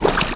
Block Tar Slidedown Sound Effect
block-tar-slidedown-2.mp3